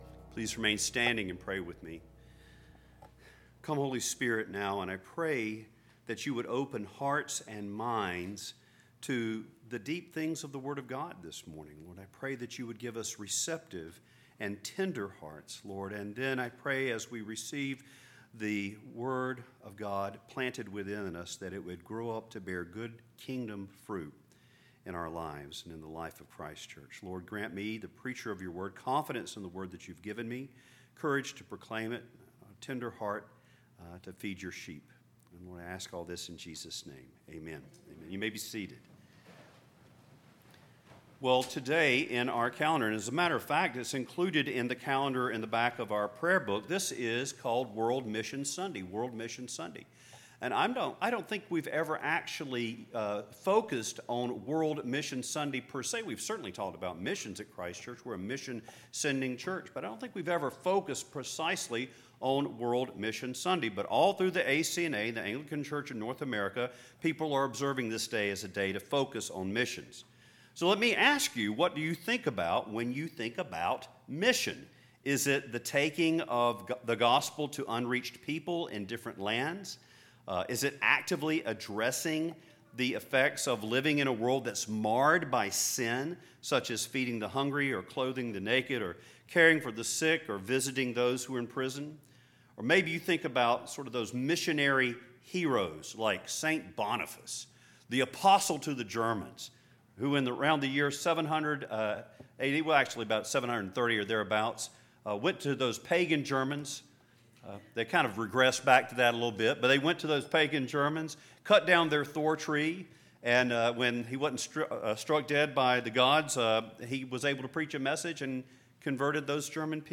Sermons
World Mission Sunday